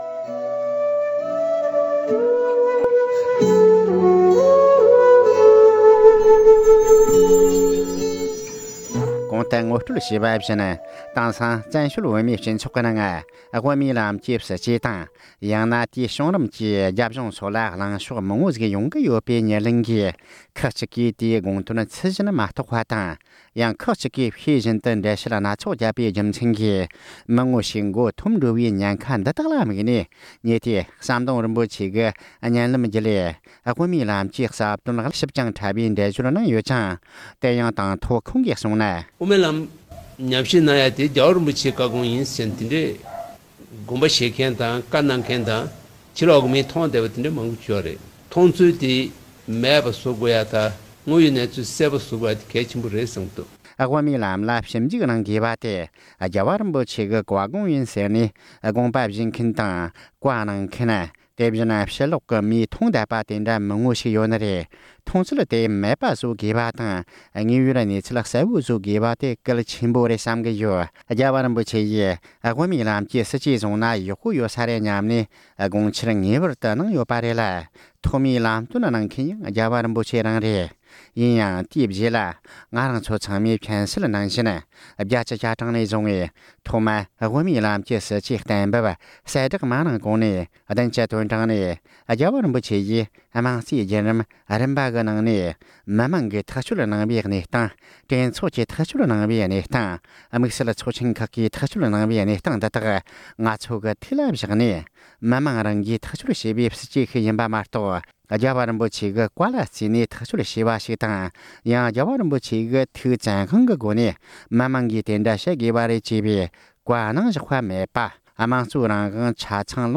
སྒྲ་ལྡན་གསར་འགྱུར། སྒྲ་ཕབ་ལེན།
༄༅།།དེ་རིང་གི་དེང་དུས་བོད་ཀྱི་ལེ་ཚན་ནང་དབུ་མའི་ལམ་གྱི་སྲིད་ཇུས་བྱུང་རིམ་དང་།  དགོས་དབང་སྐོར་མཁས་དབང་ཟམ་གདོང་རིན་པོ་ཆེས་འགྲེལ་བཤད་གནང་བ་ཁག་བོད་རྒྱལ་གྱིས་ཕྱོགས་བསྡུས་བྱས་པ་ཞིག་ལ་གསན་རོགས་ཞུ།